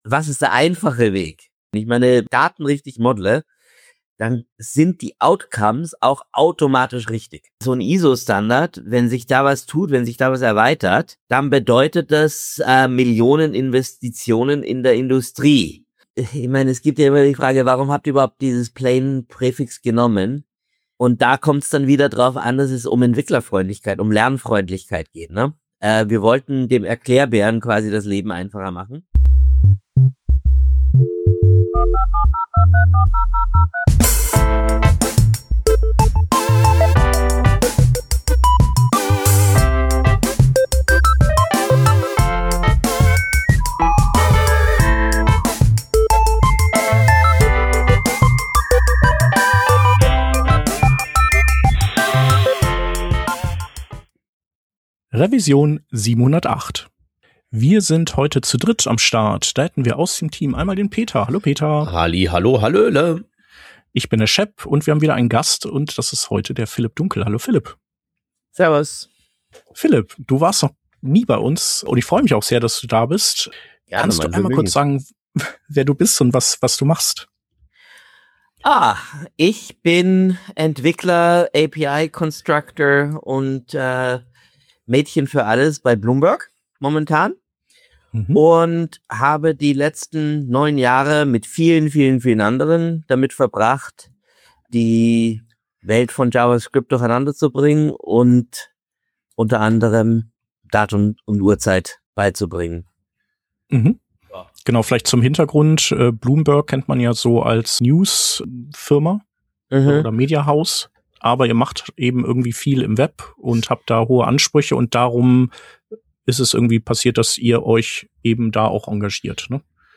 Working Draft ist ein wöchentlicher News-Podcast für Webdesigner und Webentwickler